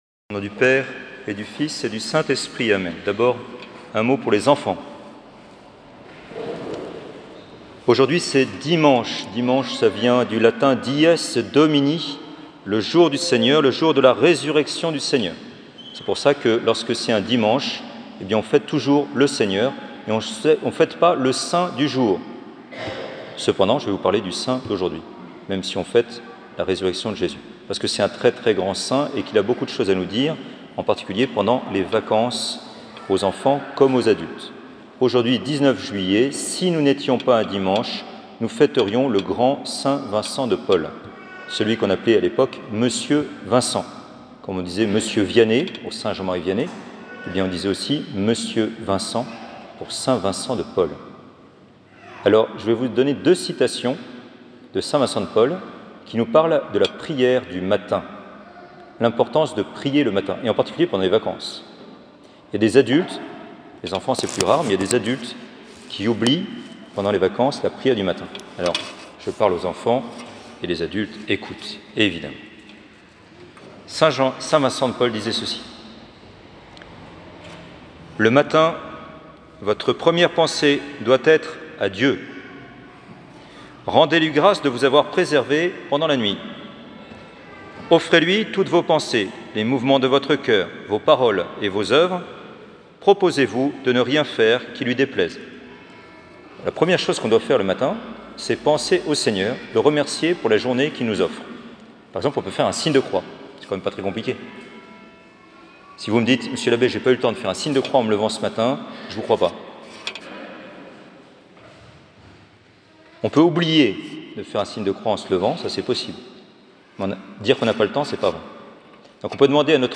Homélies du dimanche